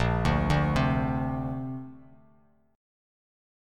A#add9 chord